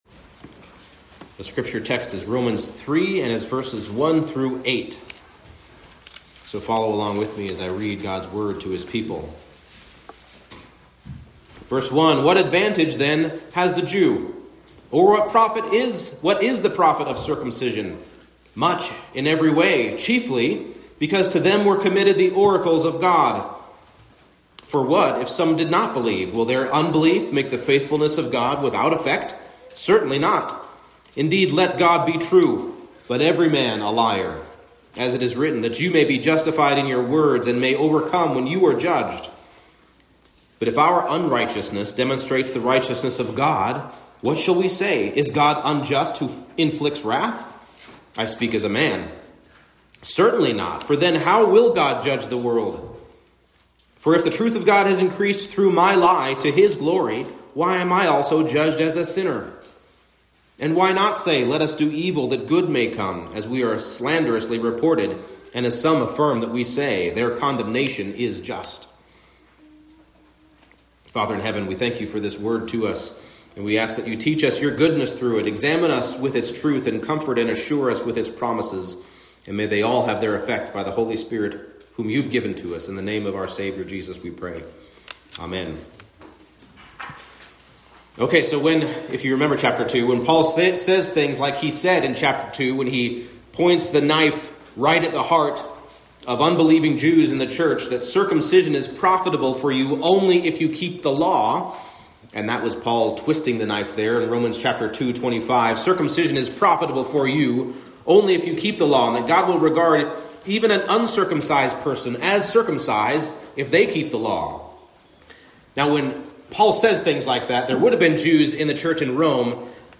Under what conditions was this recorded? Romans 3:1-8 Service Type: Morning Service Whatever the sin and unfaithfulness displayed by men